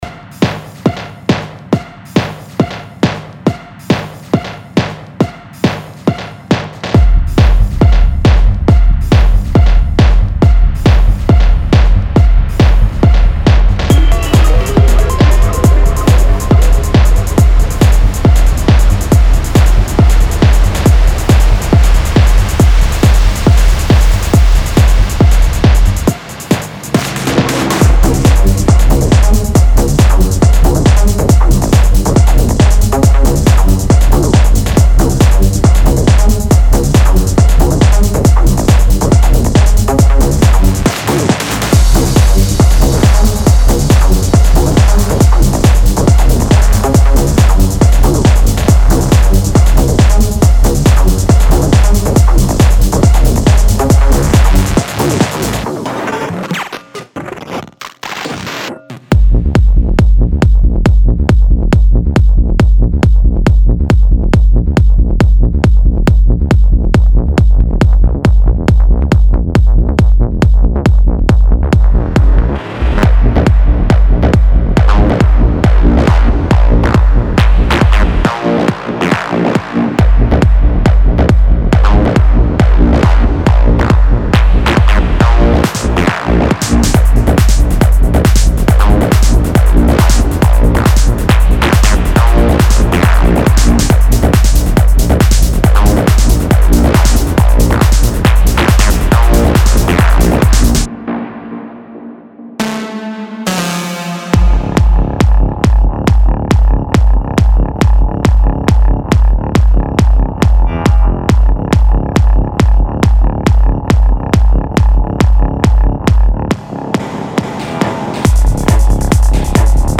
テクノ系サンプルパックをご紹介いたします。
サンプルのさまざまなグループは、次のようなさまざまなアナログ機器のチェーンによって処理されました。
・20テクノキックループ